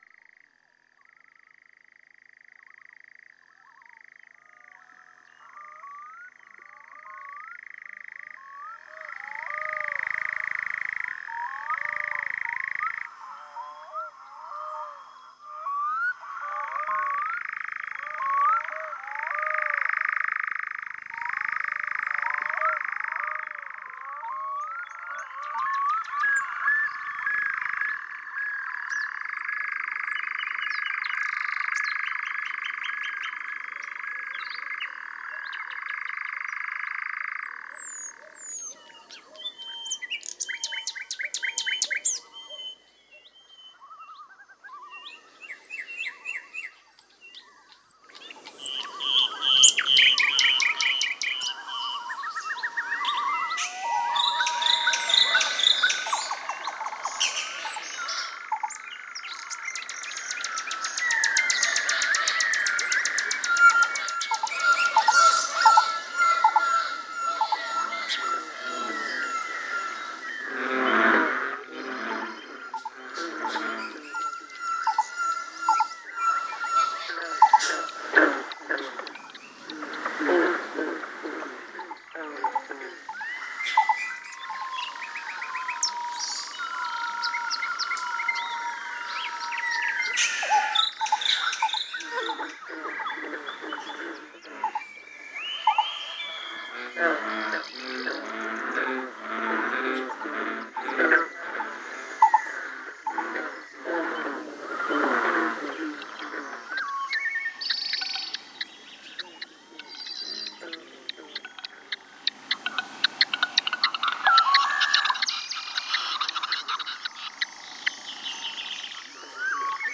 The sounds circulating in this cable tree are the songs of birds from all over the world.
The bird sounds were not treated or processed, but sometimes they seem more electronic than natural.
yellow-green ground wire, electromagnetic induction headphones, multi channel audio composition
bird-tree-Kubisch.mp3